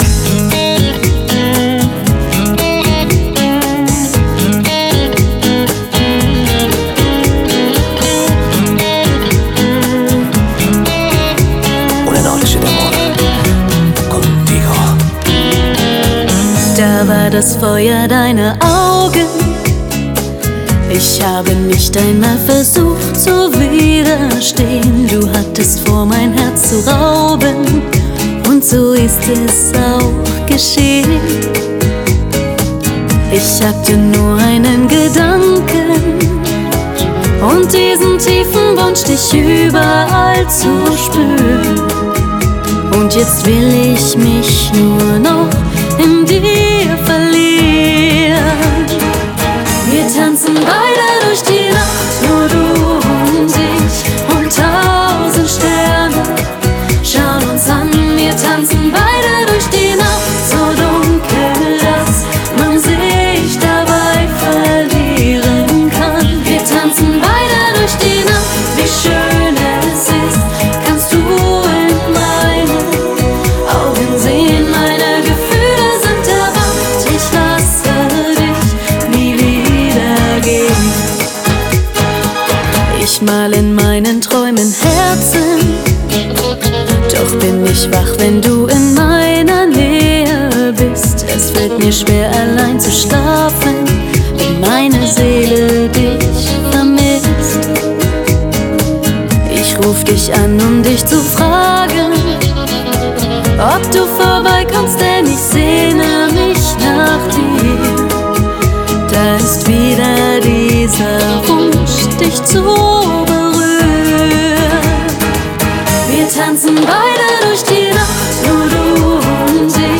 Жанр: Schlager
Genre: Schlager